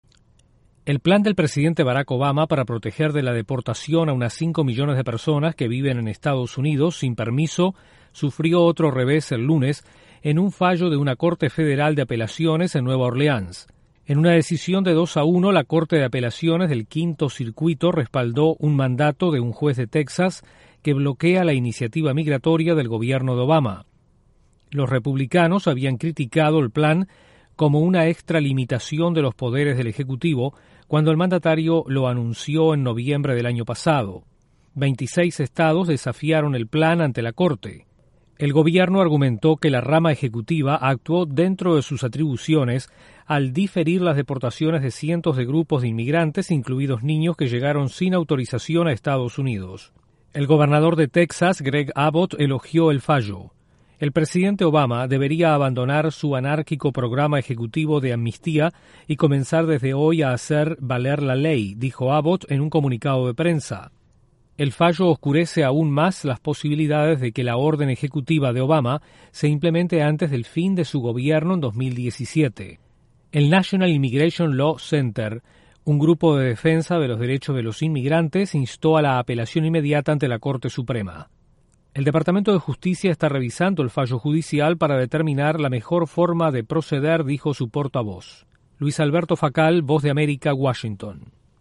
La Corte de Apelaciones en Nueva Oreleans falla contra el plan migratorio del presidente Barack Obama. Desde la Voz de América en Washington informa